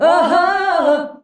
AHAAH G.wav